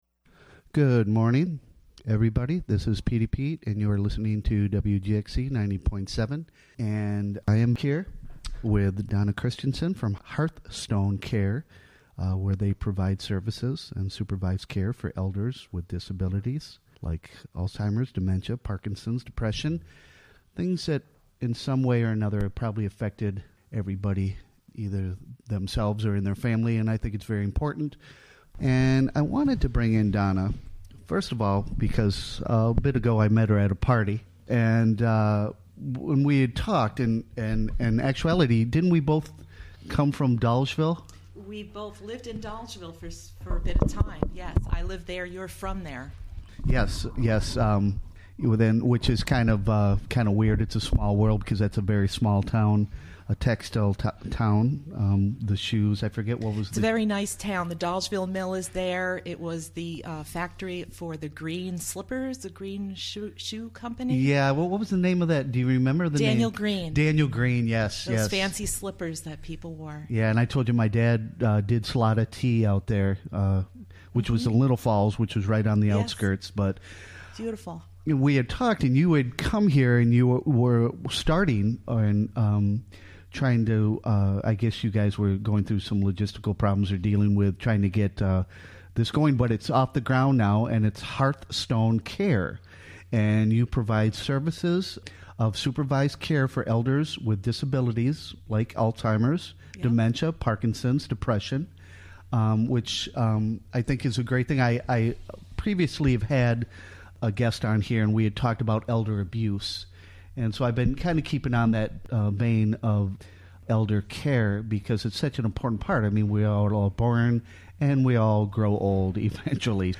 11am The WGXC Morning Show is a radio magazine show fea... Interview